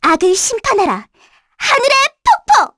Lilia-Vox_Skill4_kr.wav